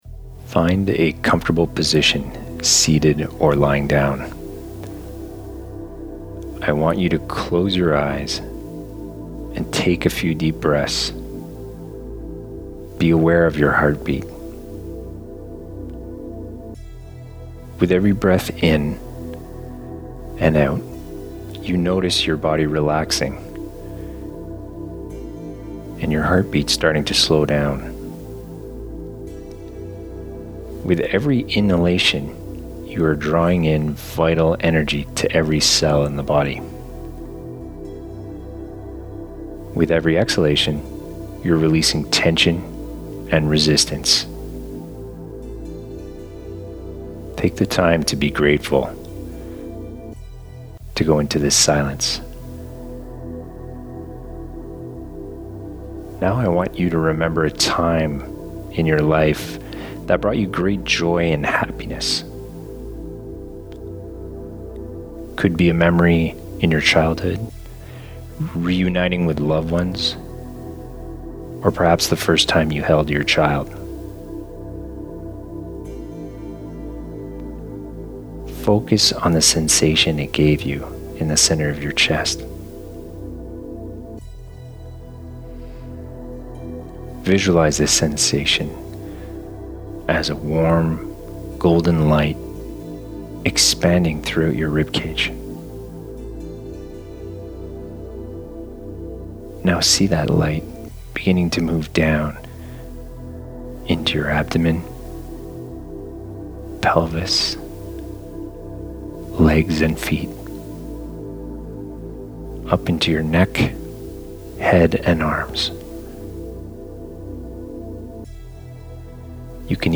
9UyllhvoQWe38PdG3EgW_Heart_Focus_Meditation.mp3